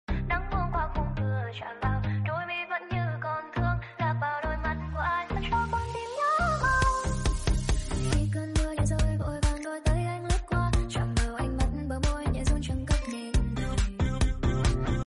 tiktok funny sound hahaha